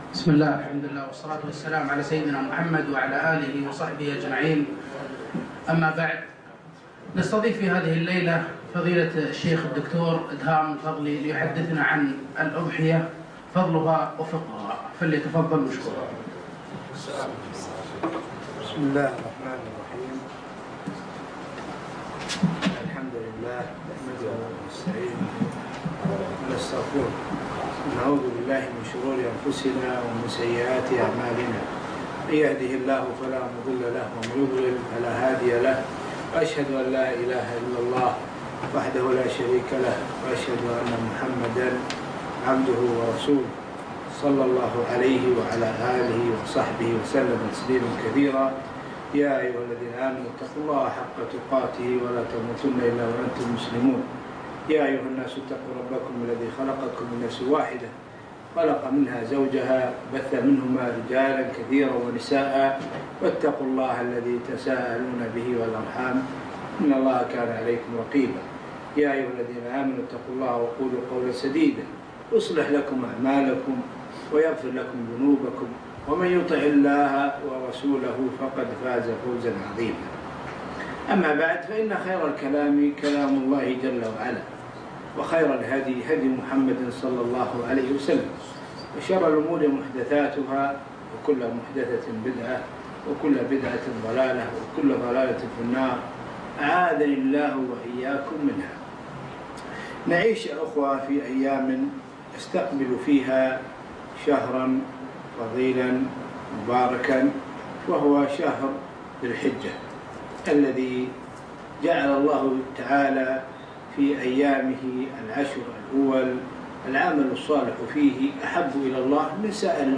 محاضرة - الأضحية فضلها وفقهها